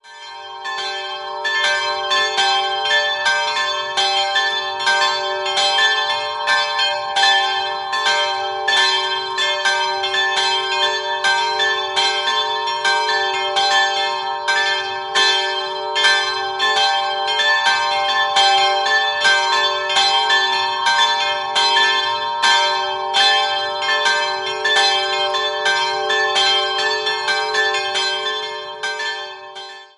3-stimmiges Gloria-Geläute: g''-a''-c''' Die kleine c'''-Glocke ist historisch, die beiden größeren (der Muttergottes und dem Kirchenpatron geweiht) wurden im Jahr 1988 von der Gießerei Perner in Passau gegossen.